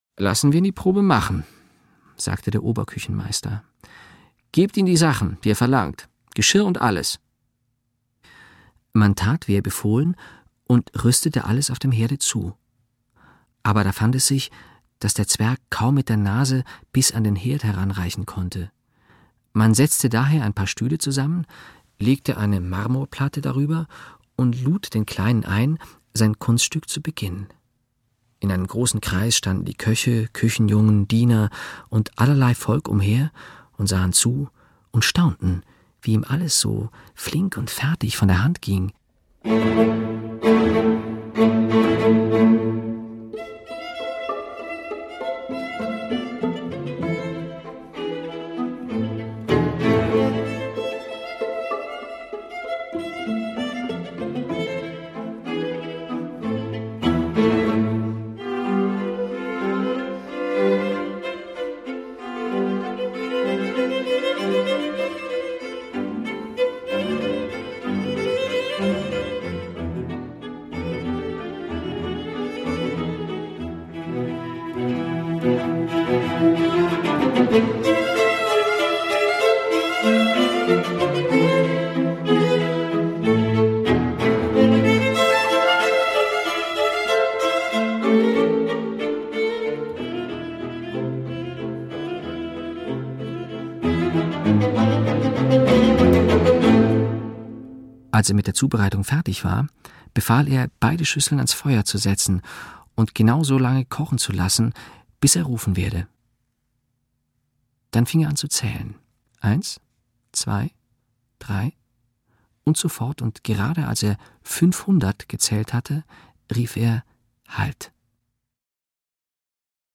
Streichquartett
Klarinette
Erzähler